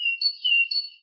mixkit-little-bird-calling-chirp-23.wav